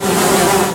flies.ogg.mp3